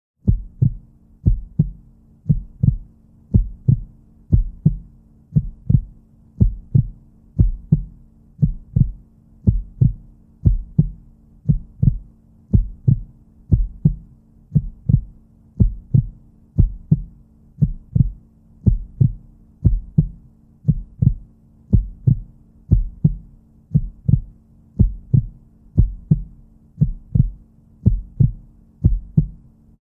Звук сердца влюбленного